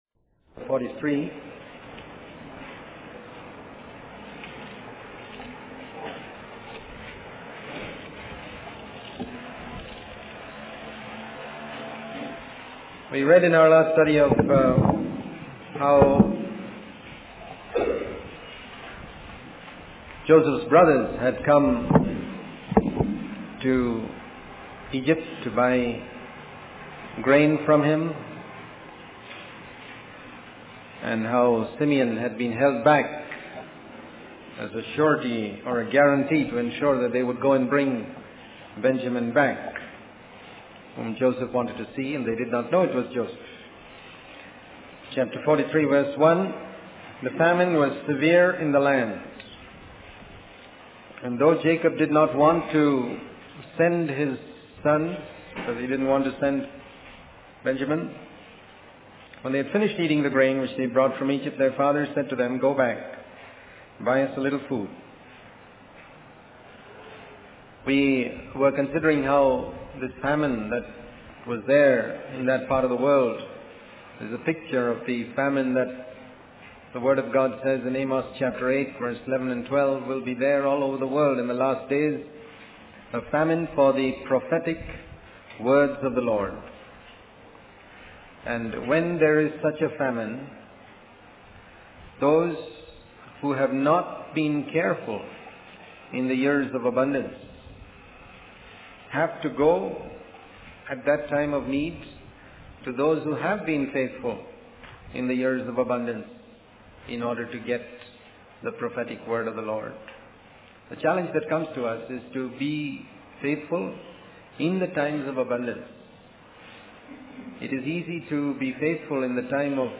In this sermon, the speaker focuses on the story of Joseph and his brothers from the book of Genesis. He highlights the events where Joseph tests his brothers by placing his silver cup in Benjamin's sack.